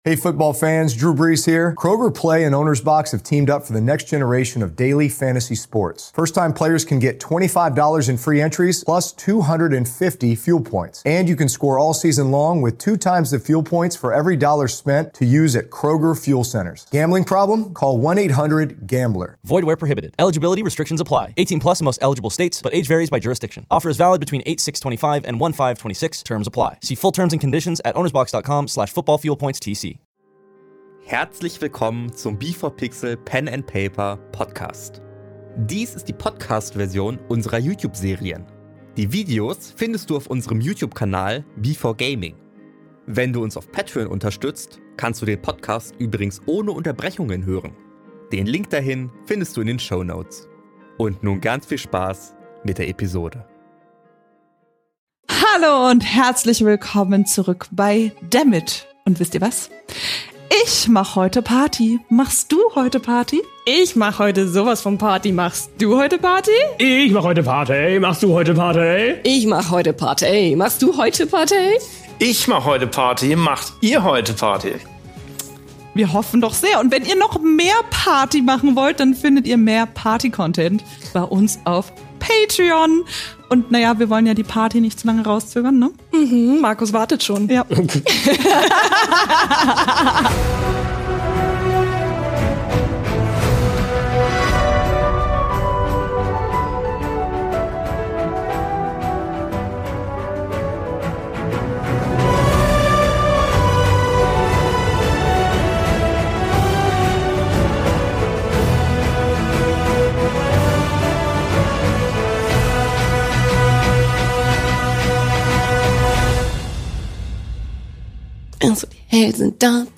Wir spielen auf dem YouTube B4Gaming regelmäßig Pen and Paper und veröffentlichen dort unsere Serien. Dies hier ist die Podcast-Version mit Unterbrechungen.